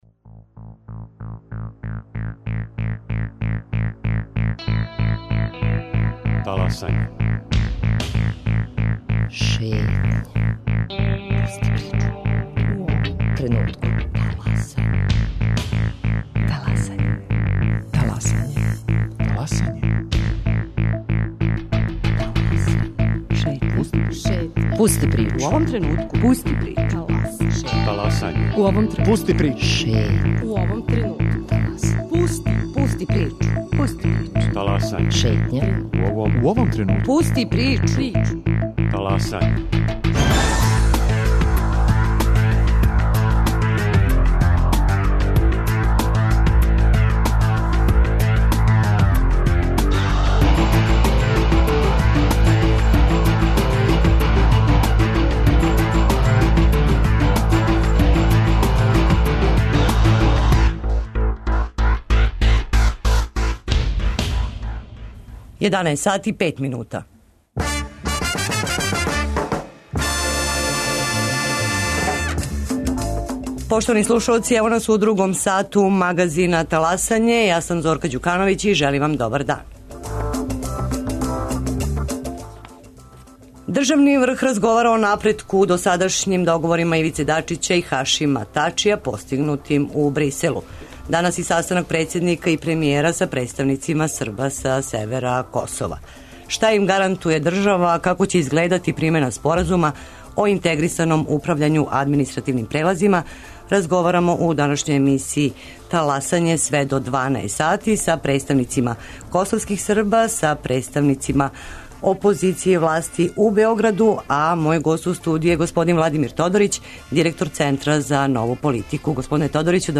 Одговоре на ова питања тражимо од власти и опозиције и од политичких представника косовских Срба.